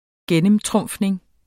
Udtale [ -ˌtʁɔmˀfneŋ ]